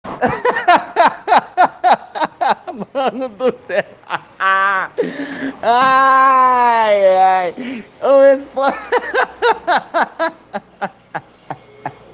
rindo muito Meme Sound Effect
rindo muito.mp3